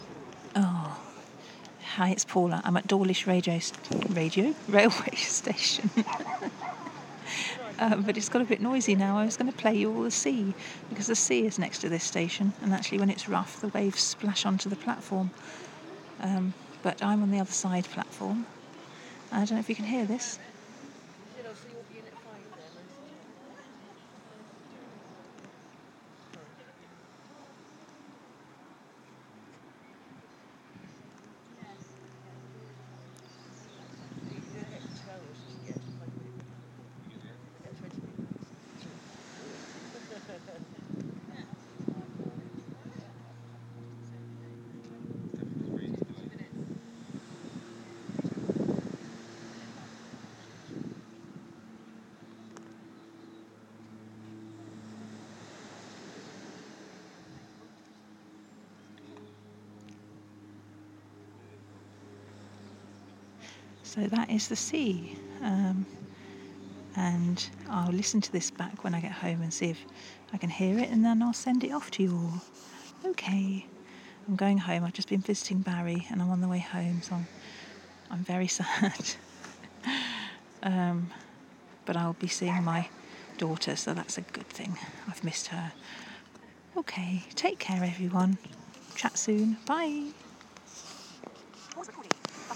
The sound of the sea at Dawlish railway station
I recorded this ages ago, and forgot to post it, you can hear the sea very faintly.